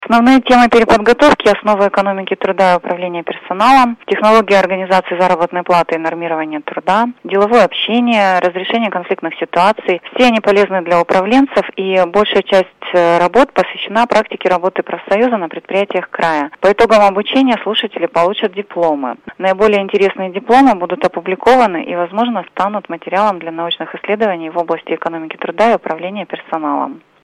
Радио "Лемма", 21 октября - 7.30, 11.30